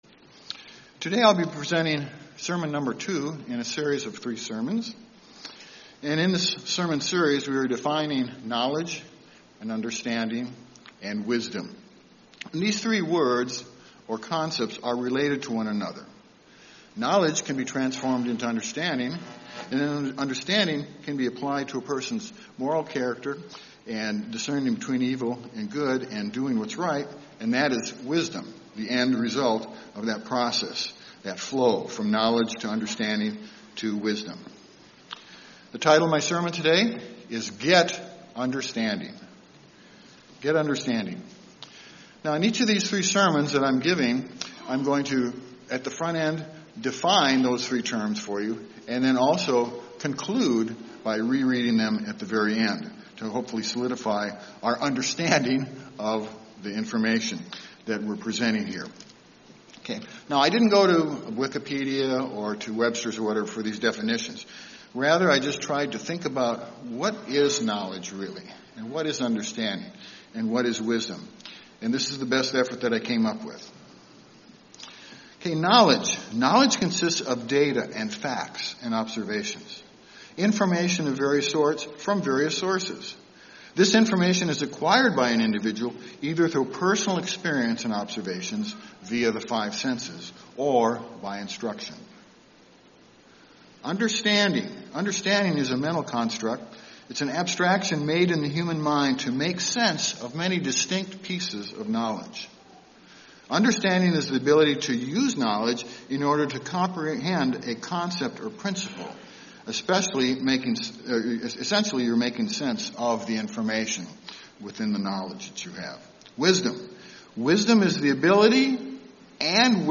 The second in a series of three sermons, examining the concepts of Knowledge, Understanding and Wisdom. In the life of every Christian, there should be a progression from that of gaining knowledge, then transforming that knowledge into understanding, and then implementing that understanding in daily life, which is wisdom.